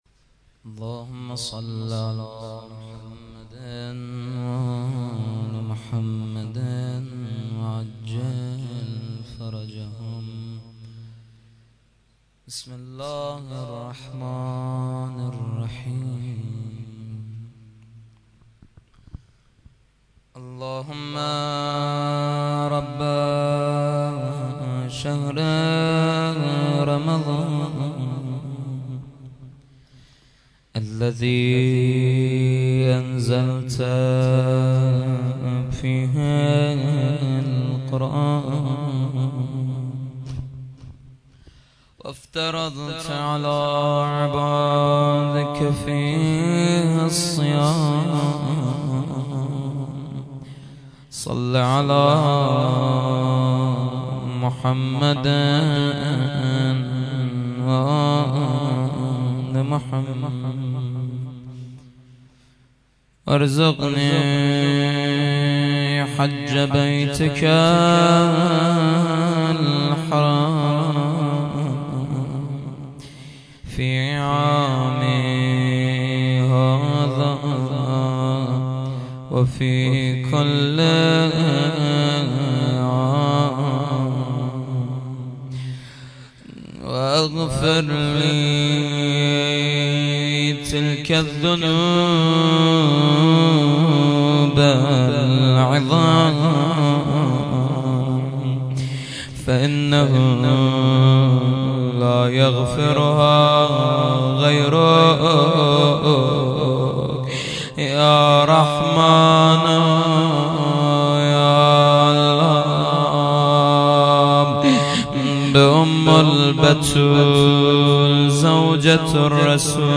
shahadat-hazrat-khadije-93-rozeh-2.mp3